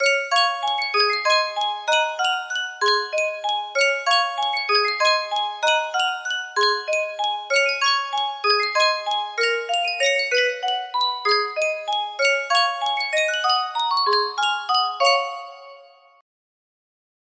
Sankyo Music Box - Unknown Tune 2 music box melody
Full range 60